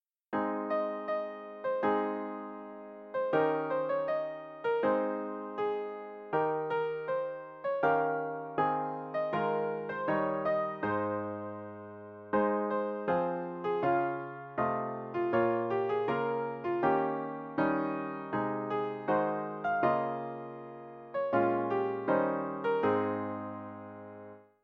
076.b-Röddin (Ég stóð.. solo+SATB)